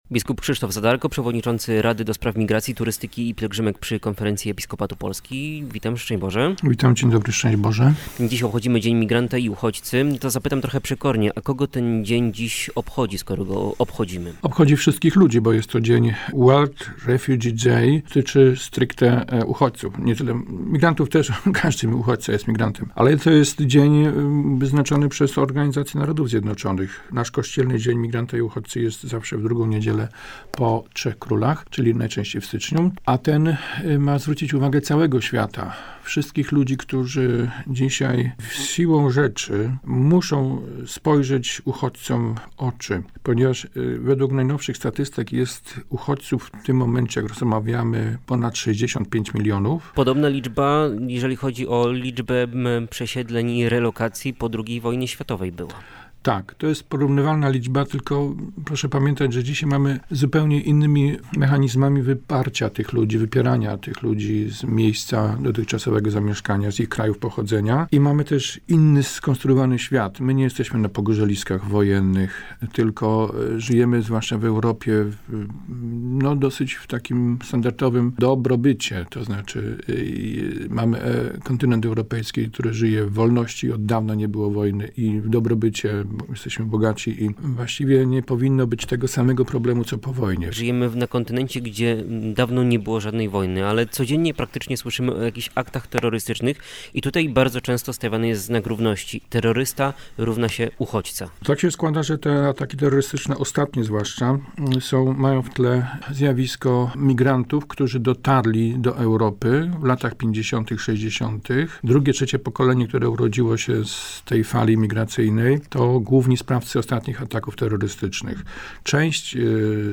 Biskup pomocniczy diecezji koszalińsko-kołobrzeskiej był gościem popołudniowej audycji - Studia Bałtyk. Pretekstem do rozmowy był obchodzony 20 czerwca Światowy Dzień Uchodźcy.